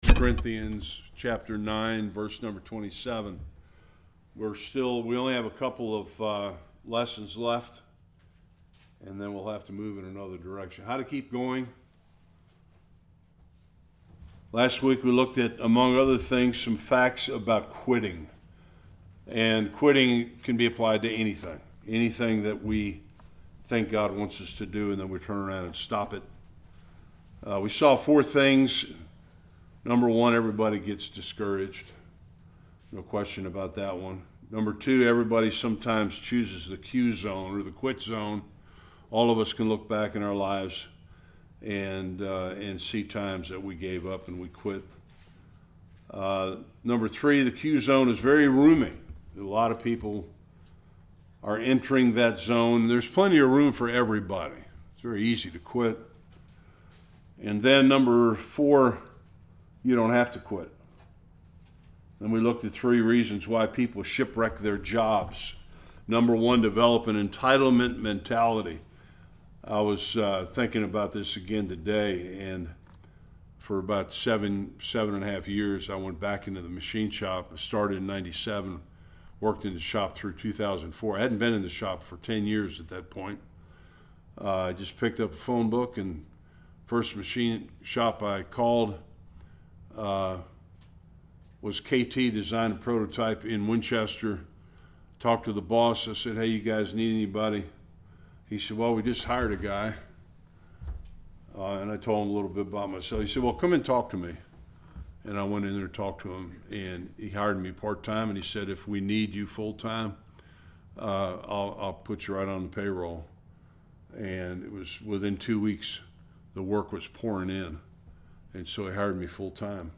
Various Passages Service Type: Bible Study What makes people shipwreck their faith?